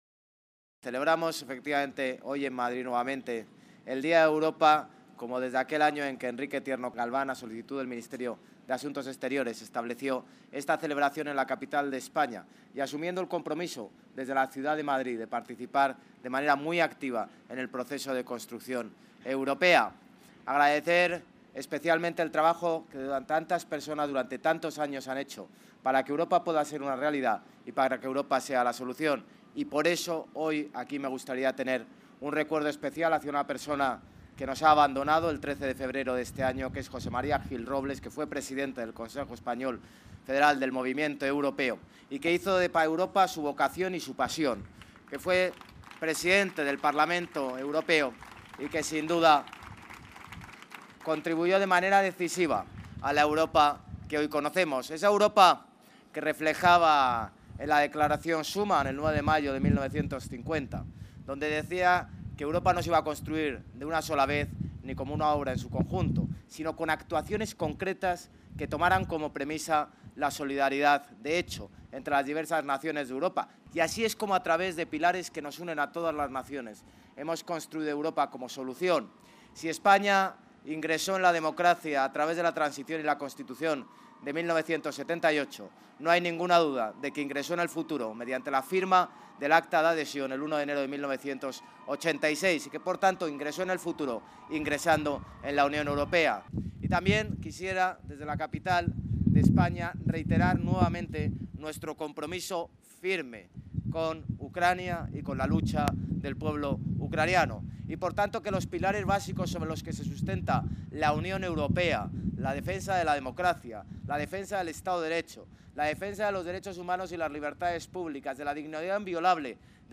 Durante el acto de conmemoración del Día de Europa, que se celebra cada 9 de mayo coincidiendo con la fecha de la Declaración Schuman
Nueva ventana:José Luis Martínez Almeida, alcalde de Madrid: